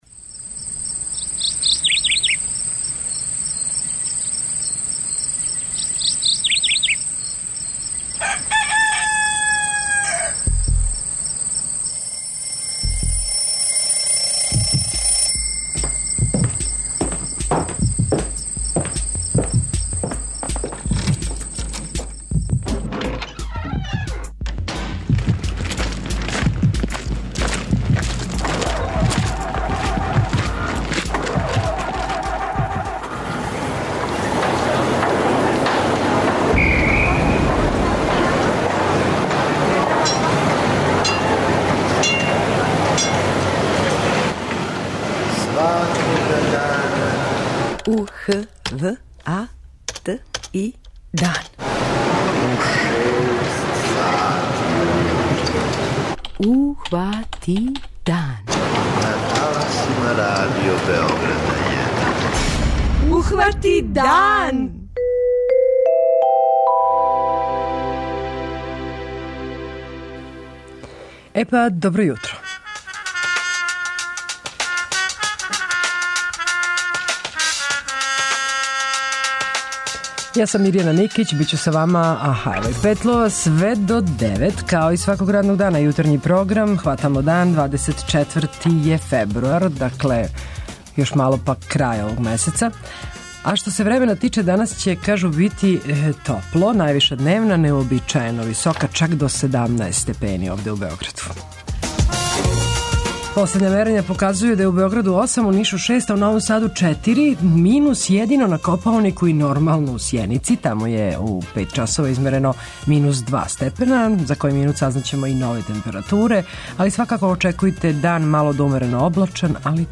преузми : 85.92 MB Ухвати дан Autor: Група аутора Јутарњи програм Радио Београда 1!